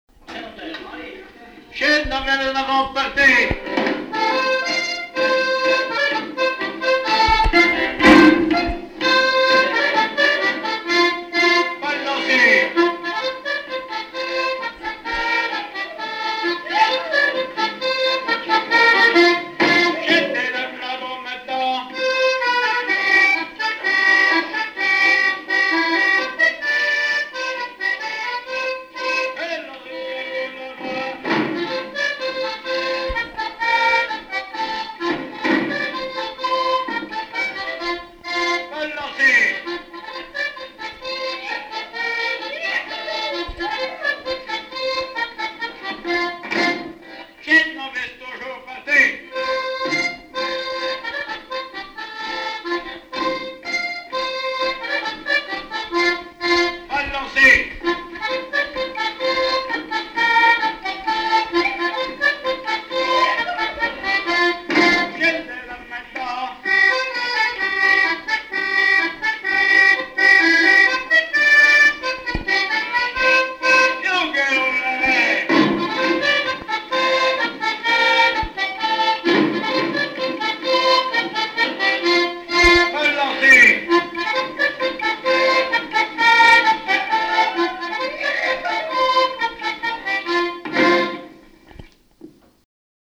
Localisation Bournezeau
danse : quadrille : chaîne anglaise
Pièce musicale inédite